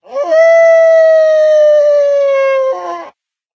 howl1.ogg